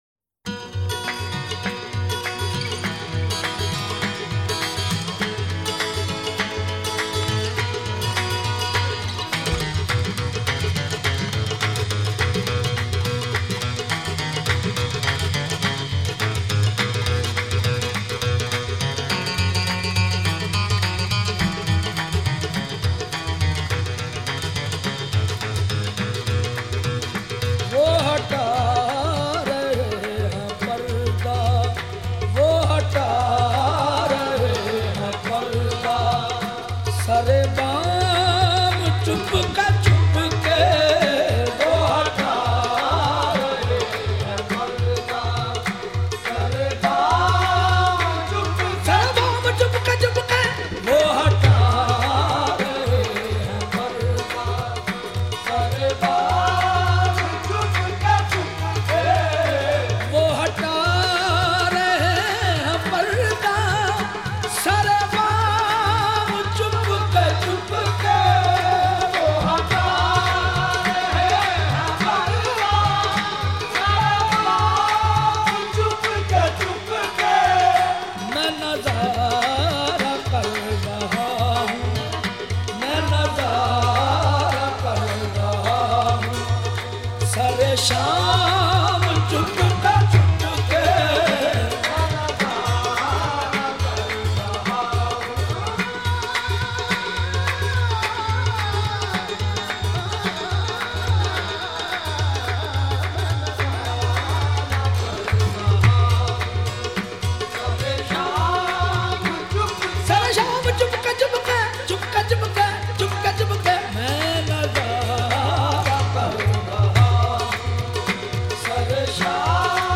Kalaam/Poetry ,ﺍﺭﺩﻭ urdu , ﭙﻨﺠﺎﺑﻰ punjabi
Ashqana Qawwali